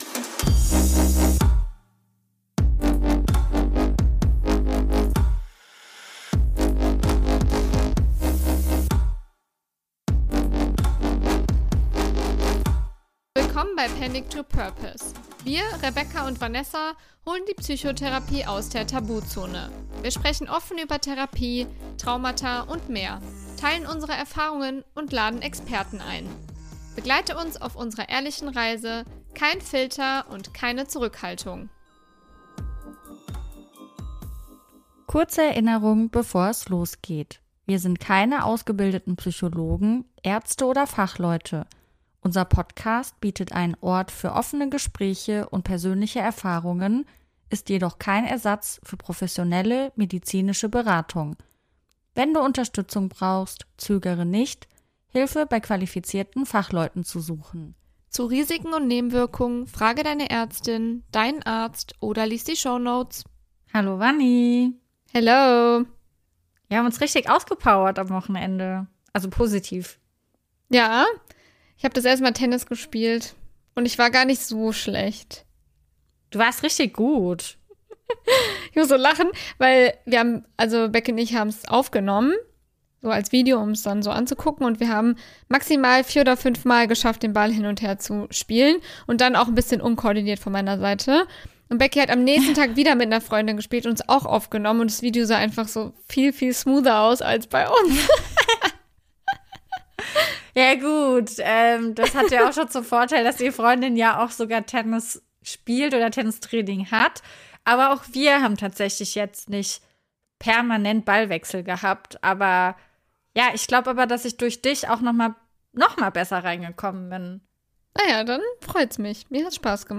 Ein ehrliches Gespräch, das Mut macht, Hoffnung schenkt und zeigt: Du bist nicht allein.